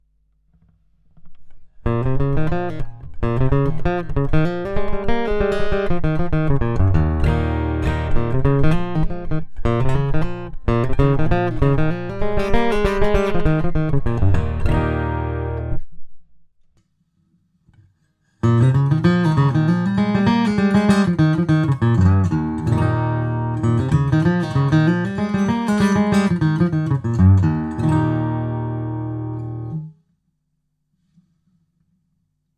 Jeweils gilt: Erste Hälfte-> reiner Pickup-Ton // Zweite Hälfte -> ToneDexter eingeschaltet.
Das muß ich folgendermaßen erläutern: Zum einen ist es schlampig hingenudelter Kram, wie er mir gerade einfiel, unsauber dahingedaddelt.
Die Aufnahmen sind mit dem voll aufgedrehten Effekt erstellt worden.
Die Aufnahmen sind mit dem Line-Out direkt in den Line-Eingang eines Zoom H4n gespielt und komplett unbehandelt. Gitarre: Martin DX1 mit einem an und für sich schon recht gut klingenden K&K pure Western.